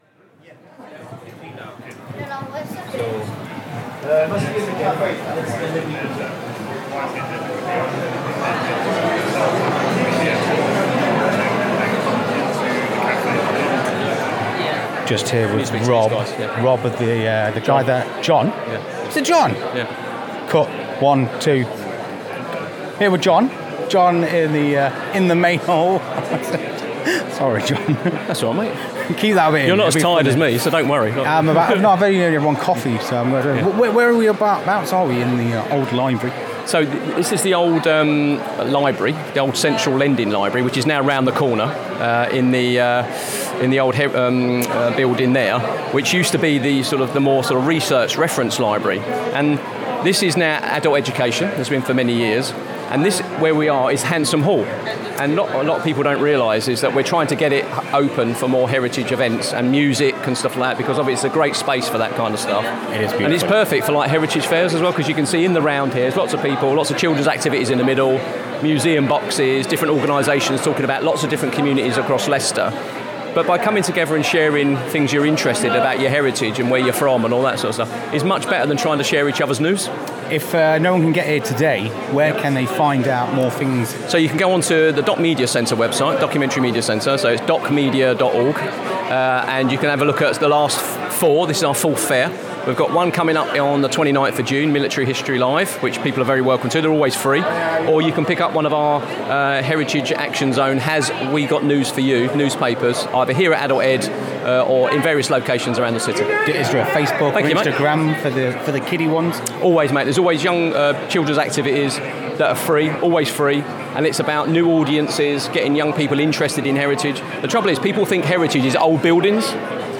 Held in the heart of Leicester, the Heritage Fair brought together an array of heritage groups and organisations, each showcasing the unique history and cultural fabric that makes our city so special.
His conversations were as diverse as the fair itself, offering a glimpse into the many stories that constitute Leicester’s heritage.